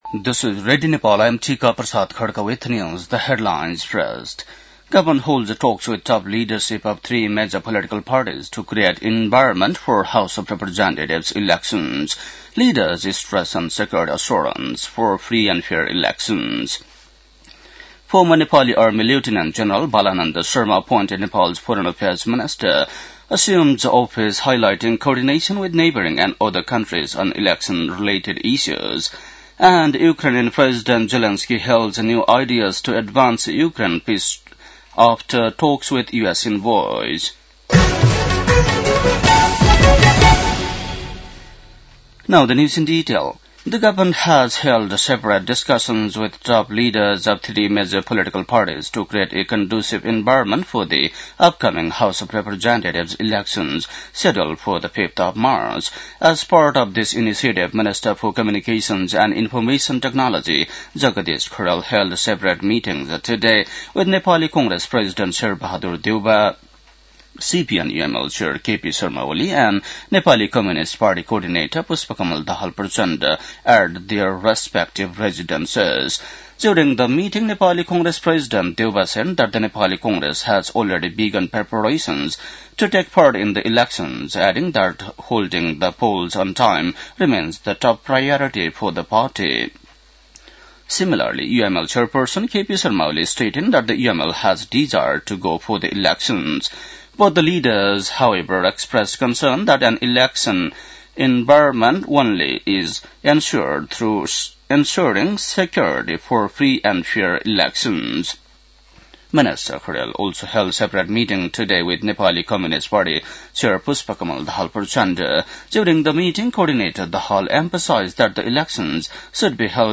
बेलुकी ८ बजेको अङ्ग्रेजी समाचार : ११ पुष , २०८२
8-pm-english-news-9-11.mp3